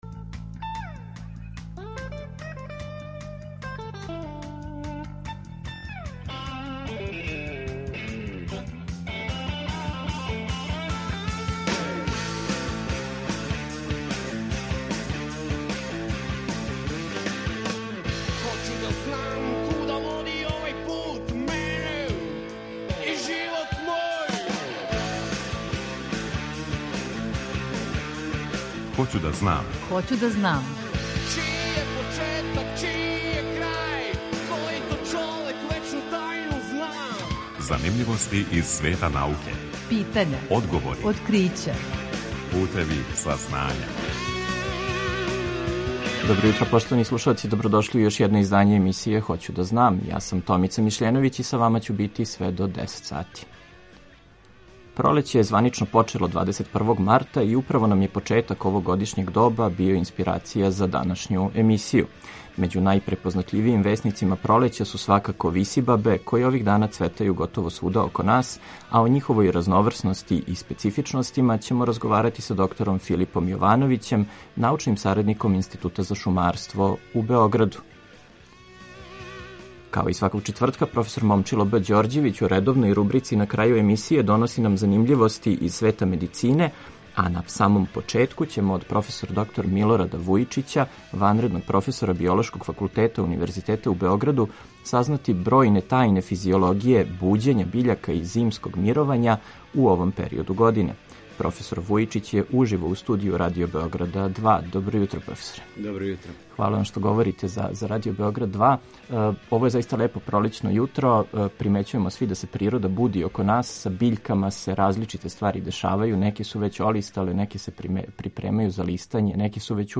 Eмисијa „Хоћу да знам“, посвећенa je популарној науци, која ће сваког четвртка од 9 до 10 сати, почев од 1. октобра 2020. доносити преглед вести и занимљивости из света науке, разговоре са истраживачима и одговоре на питања слушалаца.